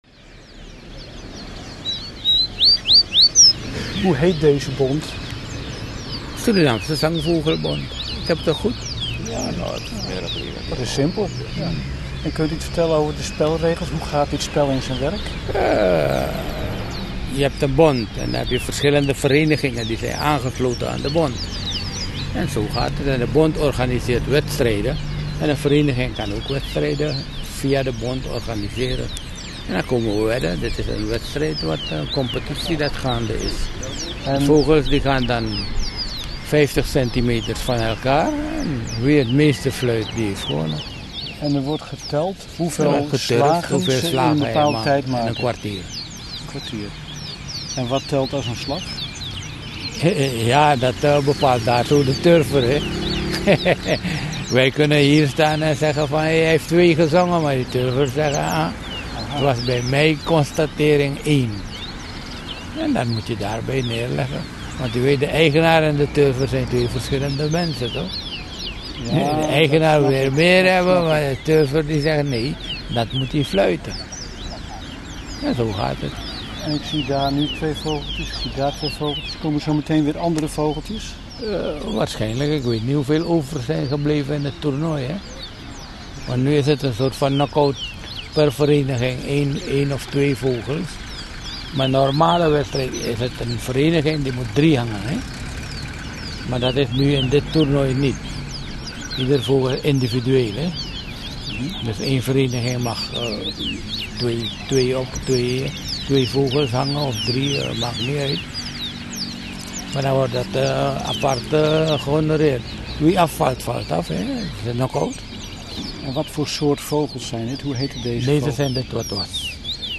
Als de wedstrijdleider het startsein geeft spits de jury de oren en bij iedere zangslag van het vogeltje wordt er een streepje geturfd.
Zangvogelwedstrijd-mono.mp3